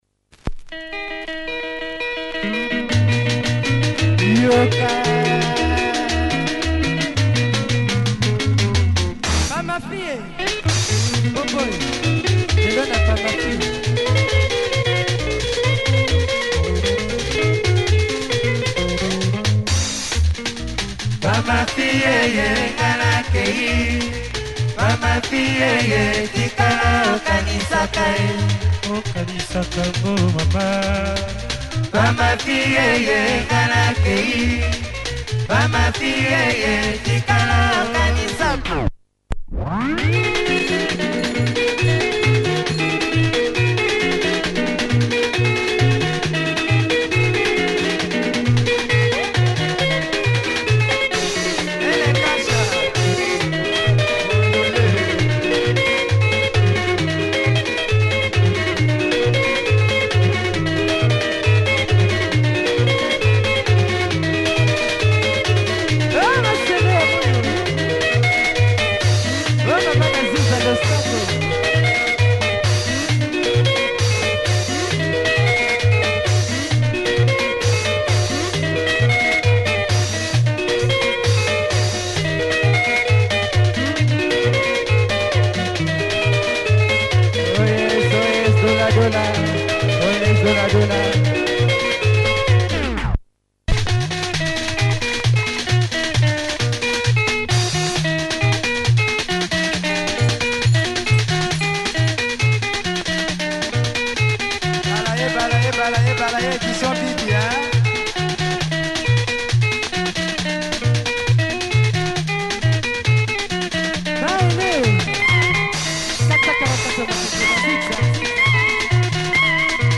Nice lingala by this congolese outfit.